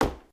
sounds / material / human / step / t_wood4.ogg
t_wood4.ogg